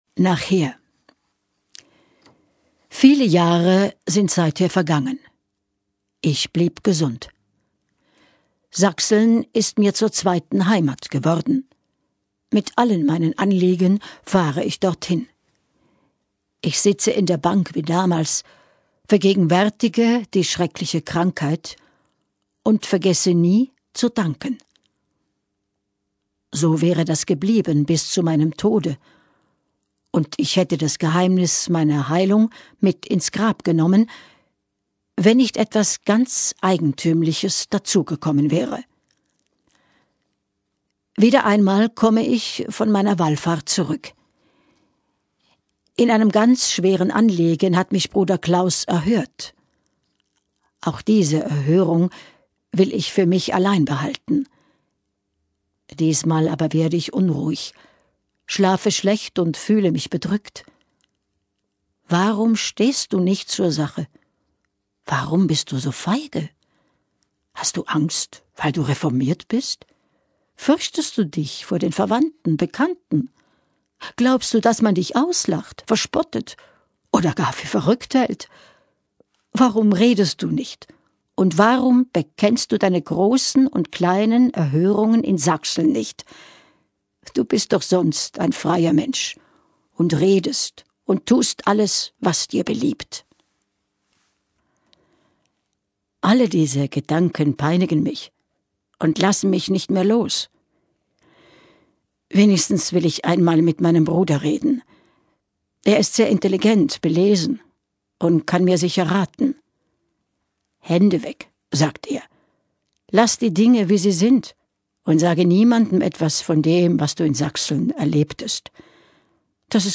Die Erzählerin